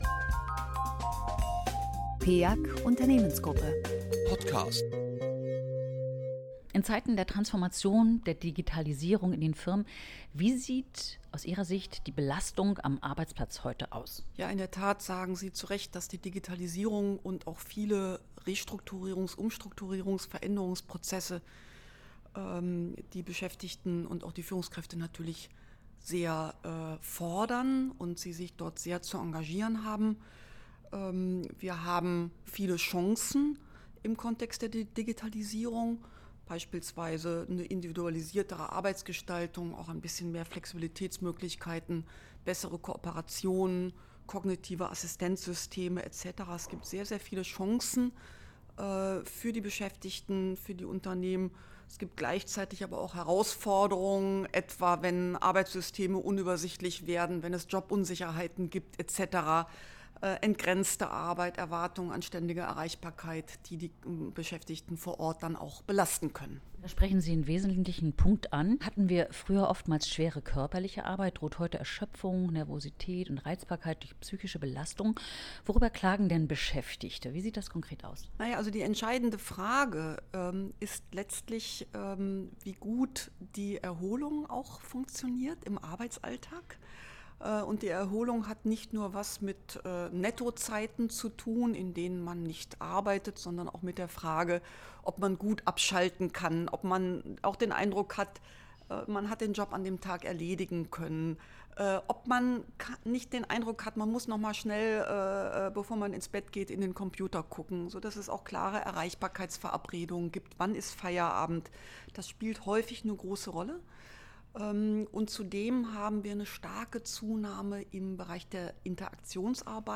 Hier finden Sie Podcasts mit O-Tönen unserer Geschäftsführer und Diskussions-Gäste, die am Rande des Frühstücks aufgezeichnet werden.
Präsidentin der Bundesanstalt für Arbeitsschutz und Arbeitsmedizin (BAuA)